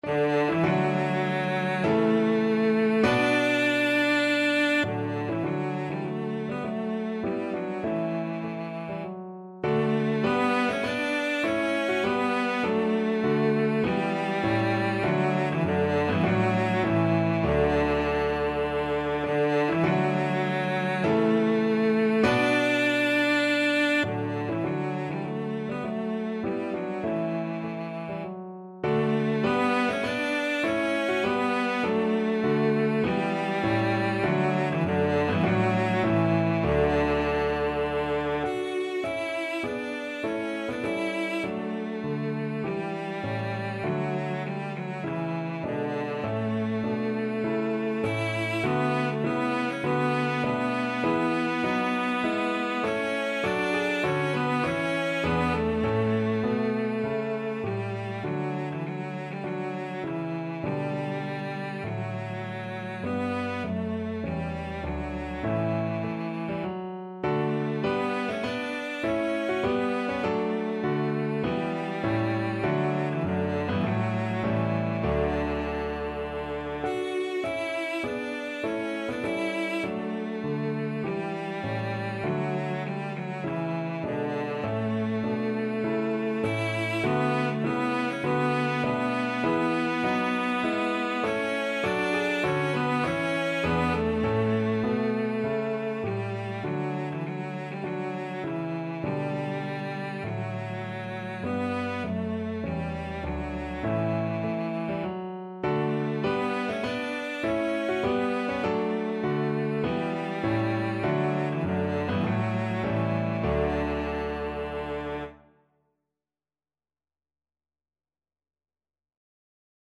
Cello version
4/4 (View more 4/4 Music)
Classical (View more Classical Cello Music)